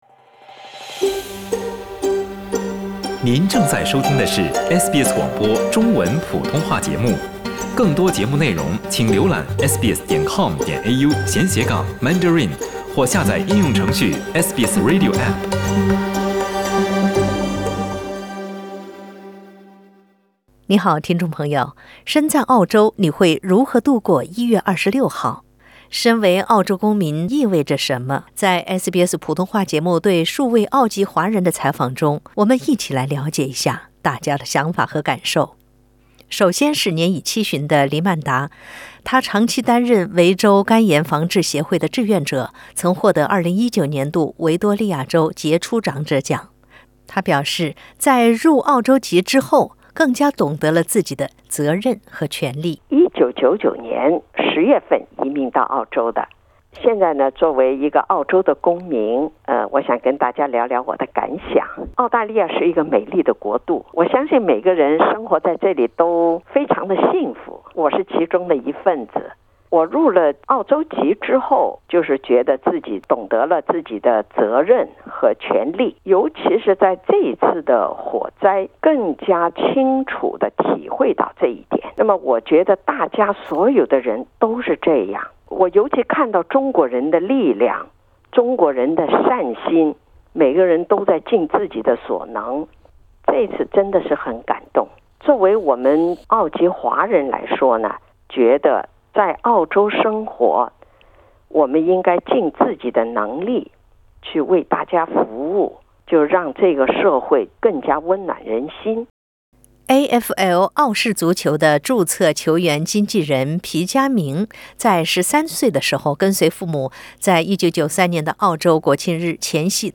在我们对数位澳籍华人的采访中，有人说庆祝澳洲带来的一切，有人说爱澳洲爱它的一切，有人说应尽自己所能让社会更温暖……身在澳洲，你会如何度过1月26日？